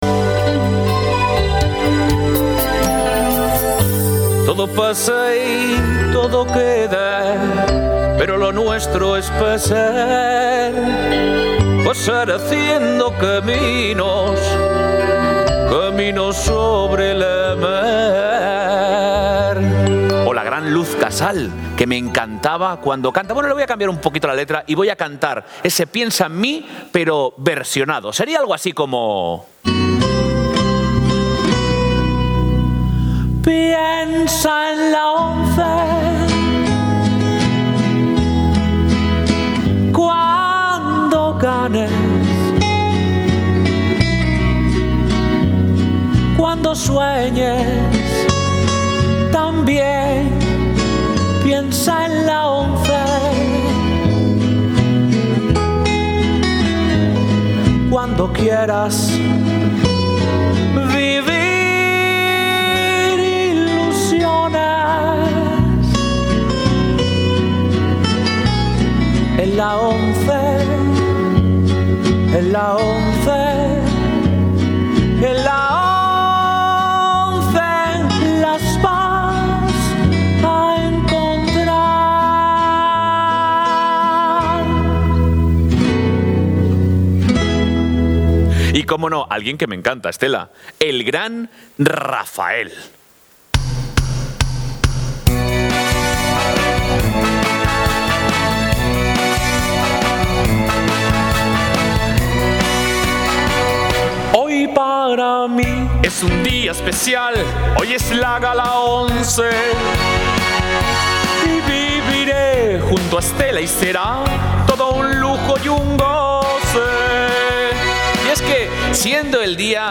Entre tanto, el popular Latre dio una magnífica muestra de su portentoso talento como imitador.
cantantes formato MP3 audio(3,38 MB), como de los